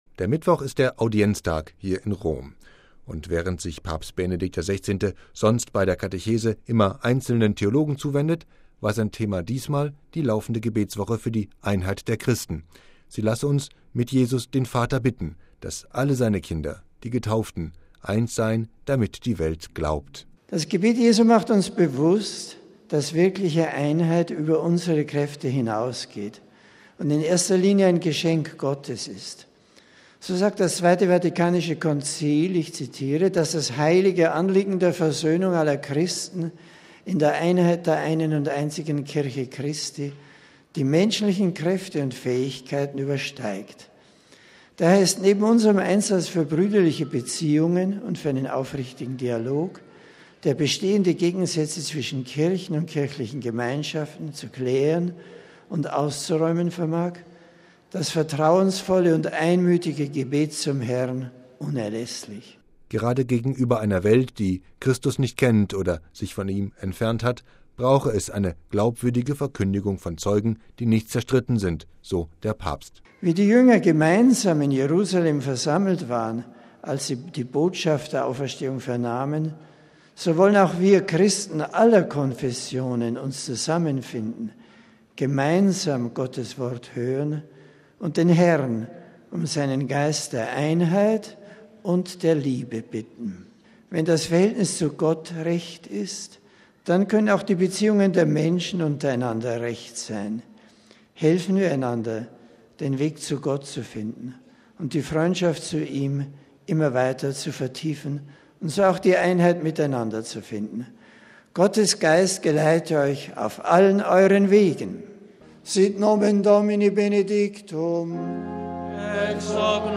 MP3 Der Mittwoch ist der Audienztag hier in Rom. Und während sich Papst Benedikt XVI. sonst bei der Kathechese immer einzelnen Theologen zuwendet, war sein Thema diesmal die laufende Gebetswoche für die Einheit der Christen.